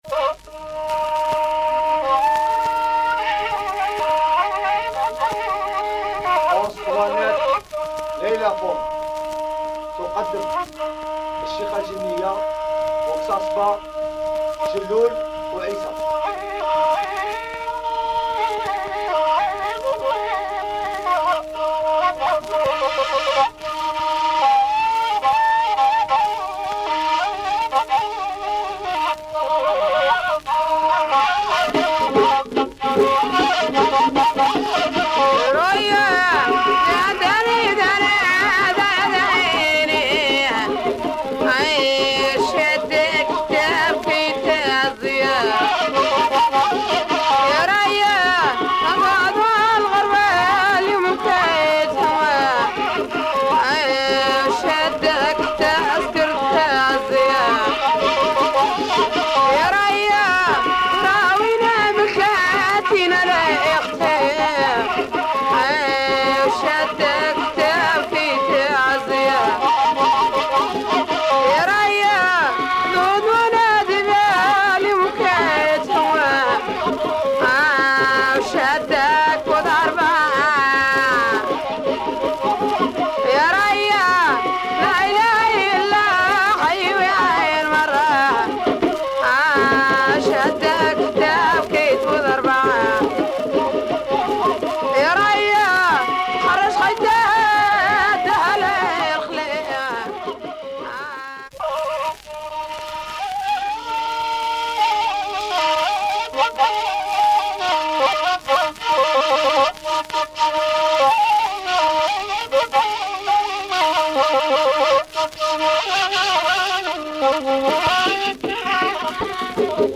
Nevermind, the music is deep, percussions, chants and flute.